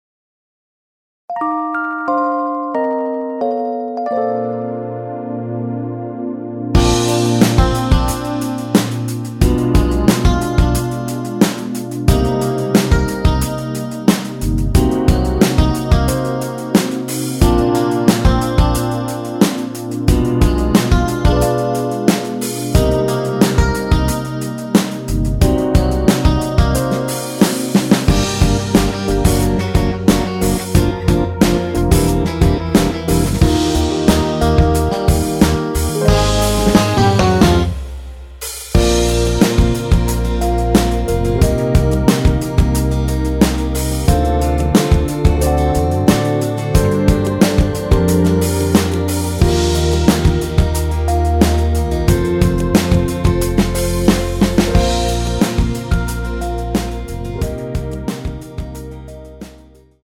원키에서(-1)내린 MR입니다.
Bb
◈ 곡명 옆 (-1)은 반음 내림, (+1)은 반음 올림 입니다.
앞부분30초, 뒷부분30초씩 편집해서 올려 드리고 있습니다.
중간에 음이 끈어지고 다시 나오는 이유는